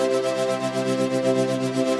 SaS_MovingPad01_120-C.wav